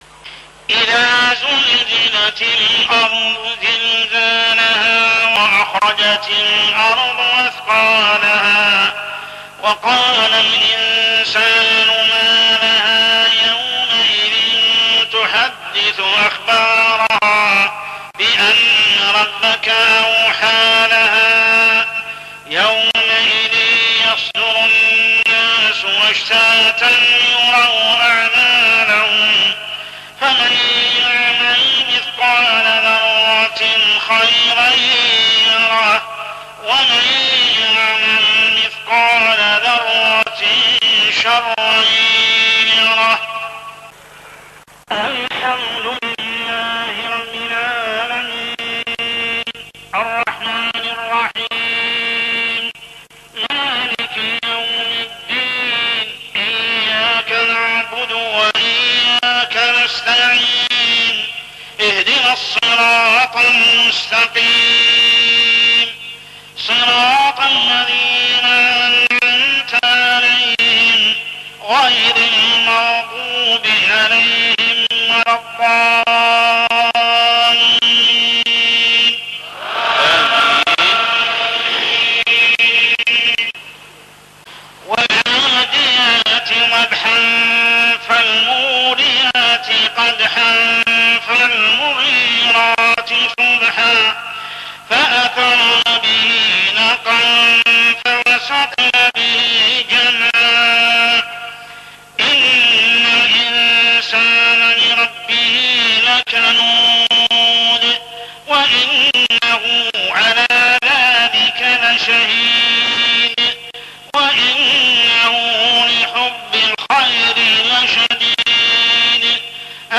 صلاة العشاء عام 1416هـ سورتي الزلزلة و العاديات كاملة | Isha prayer Surah Az-Zalzalah and Al-Adiyat > 1416 🕋 > الفروض - تلاوات الحرمين